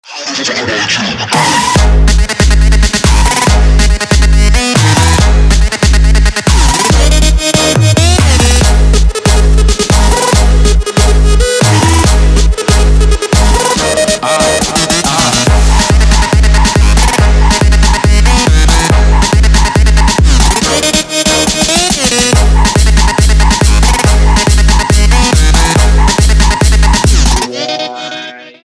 Trap
трэп